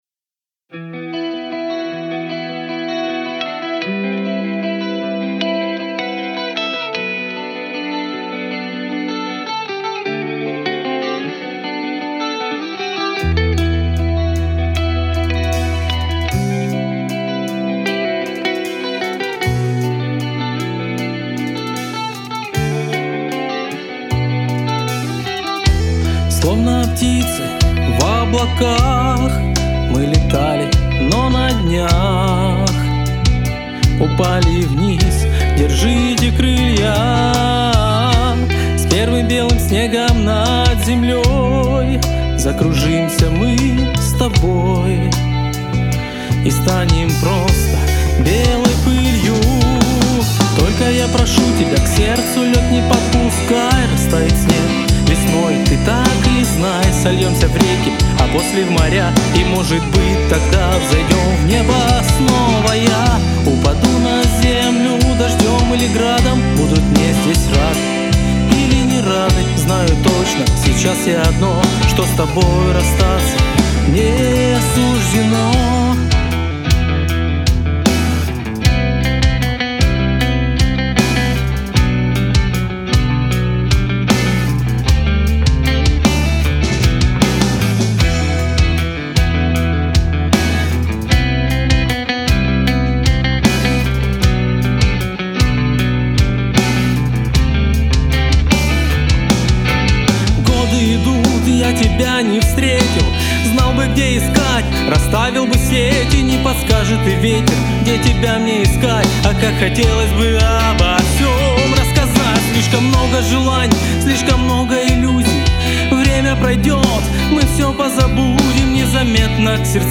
вокал
гитара
бас
ударные